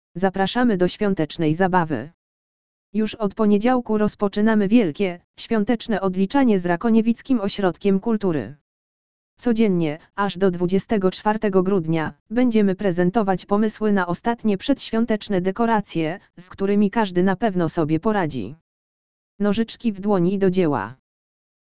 audio_lektor_swiateczne_odliczanie.mp3